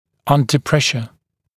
[‘ʌndə ‘preʃə][‘андэ ‘прэшэ]под давлением